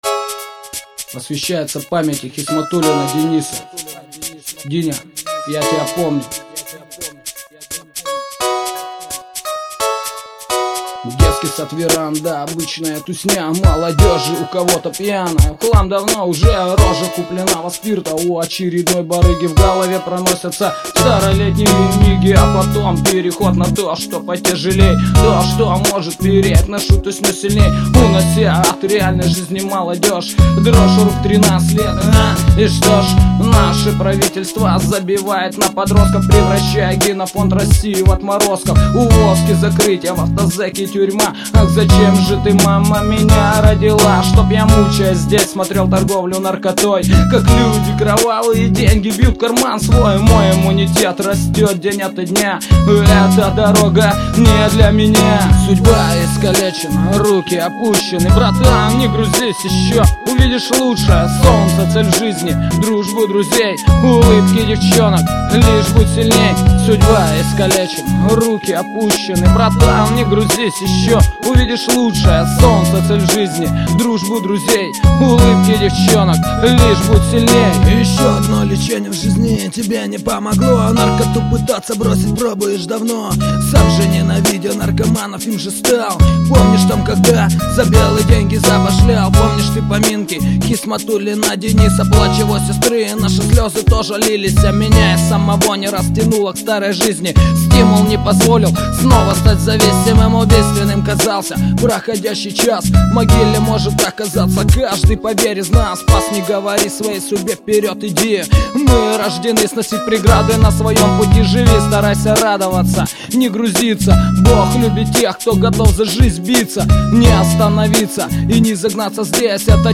Хип-хоп из Сибири.
• Жанр: Хип-хоп